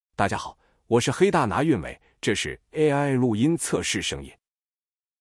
AI配音神器，热门声音模型助力创作！
各种声音模型都可以用，并且可以调节倍速下载，下面是我随即挑选的几款声音预览：